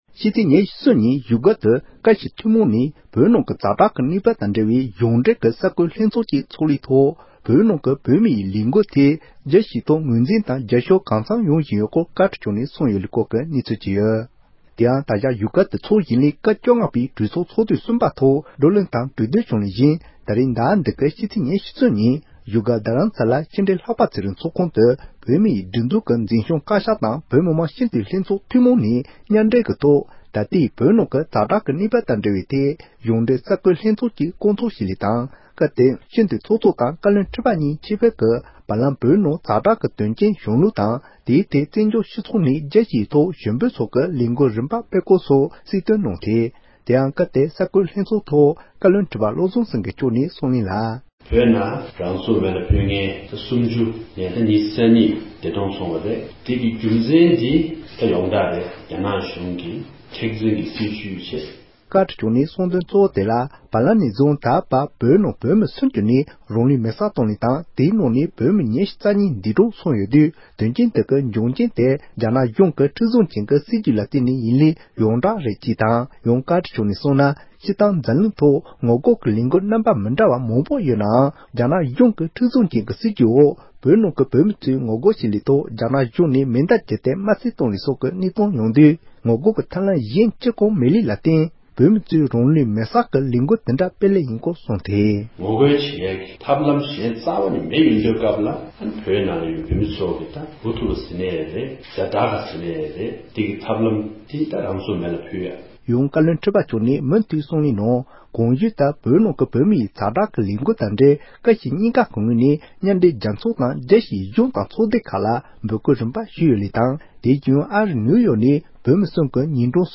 བཀའ་སྤྱིའི་གསར་འགོད་ལྷན་ཚོཌ།
སྒྲ་ལྡན་གསར་འགྱུར། སྒྲ་ཕབ་ལེན།